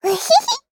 Taily-Vox-Laugh_jp.wav